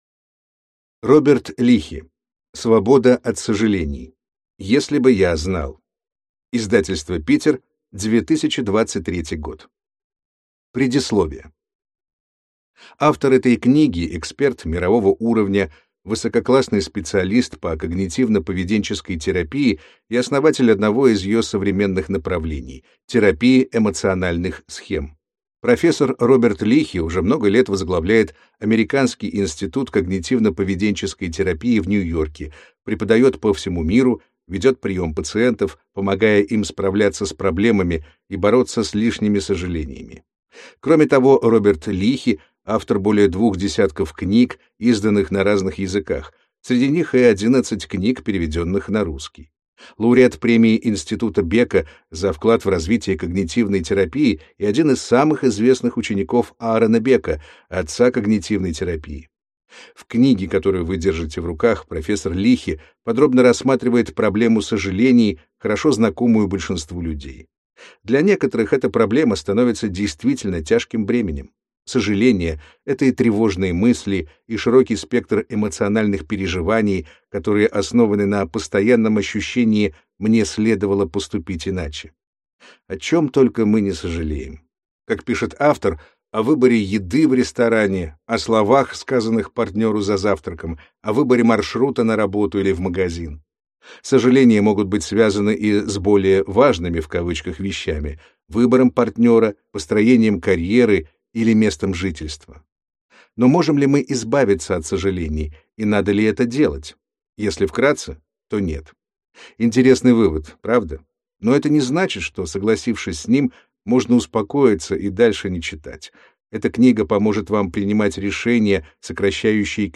Аудиокнига Свобода от сожалений.